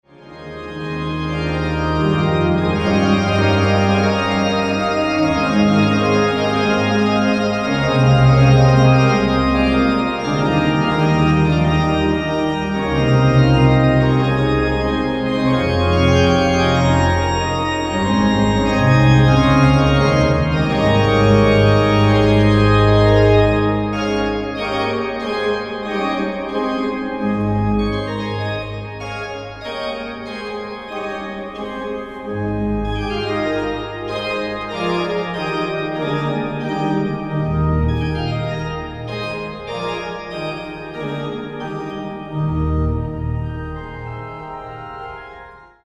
Orgel